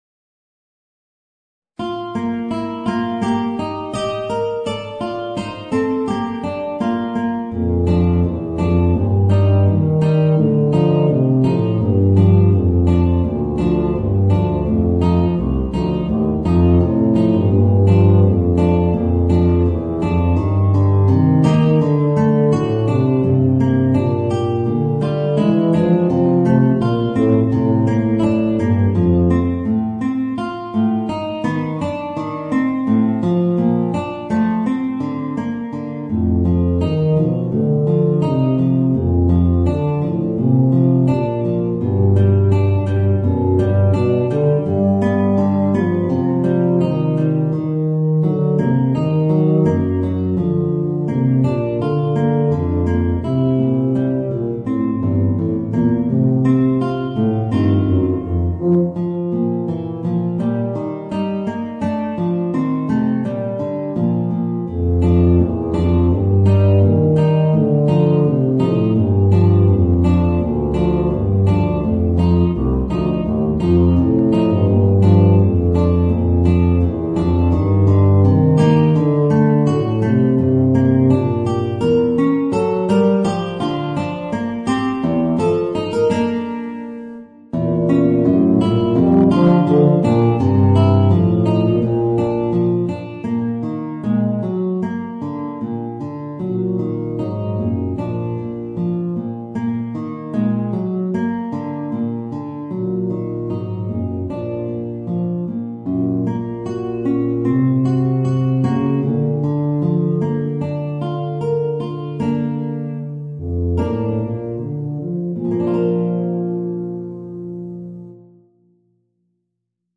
Voicing: Tuba and Guitar